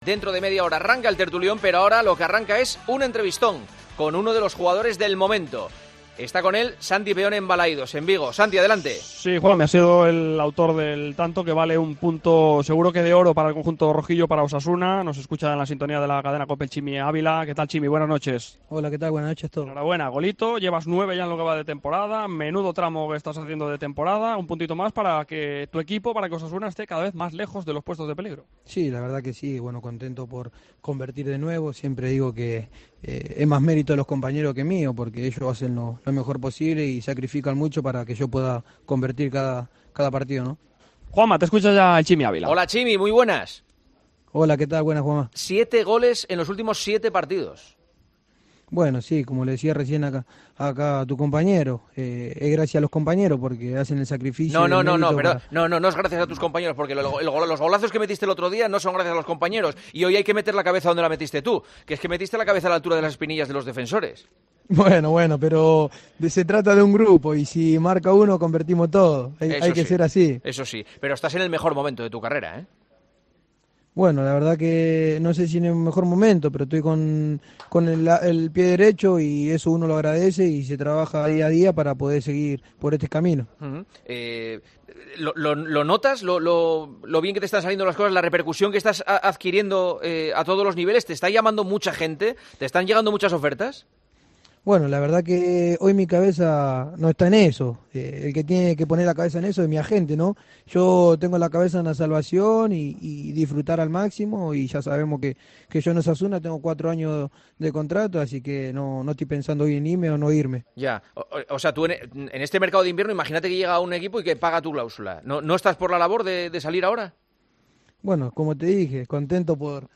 Entrevista que le realiza Juanma Castaño tras finalizar el partido frente al Celta de Vigo con gol del delantero rojillo